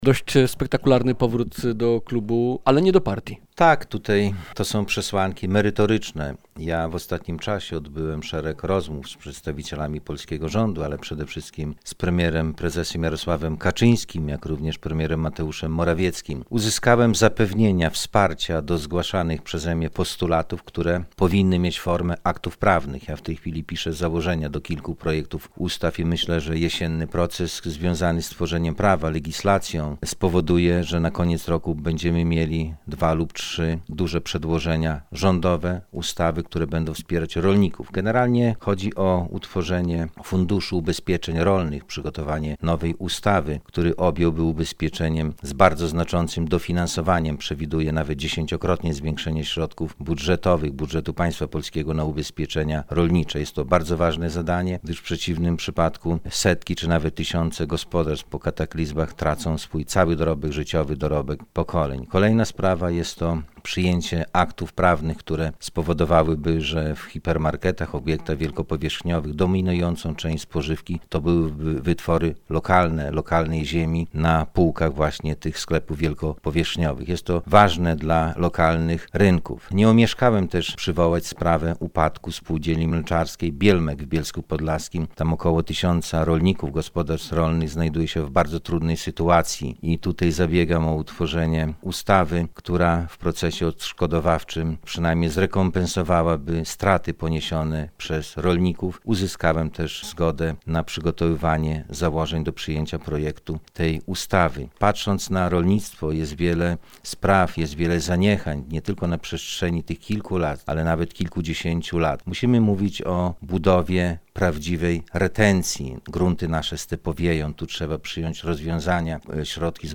Radio Białystok | Gość | Lech Kołakowski - podlaski poseł
podlaski poseł